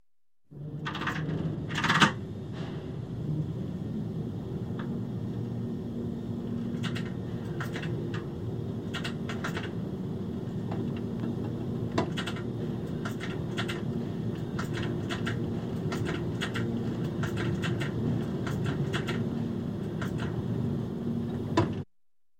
На этой странице представлены звуки башенного крана — мощные и ритмичные шумы строительной техники.
Звук крановщика при переключении рычагов